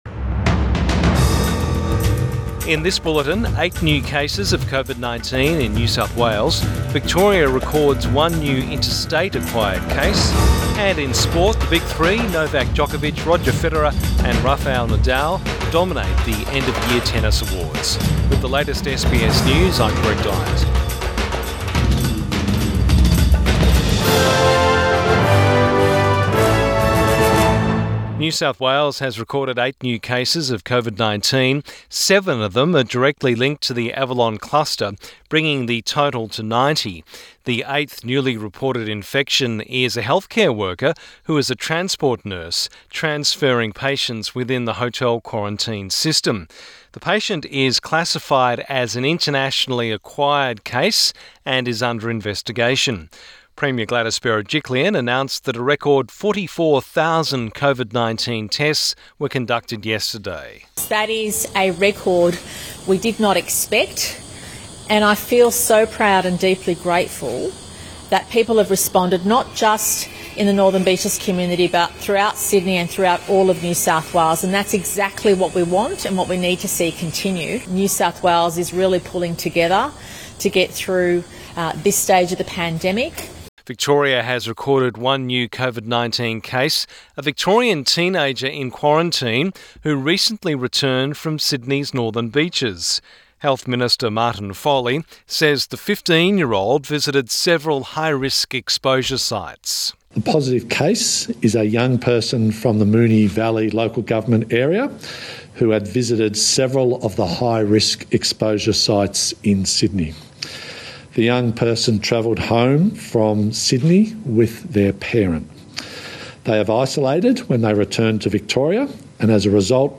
Midday bulletin 22 December 2020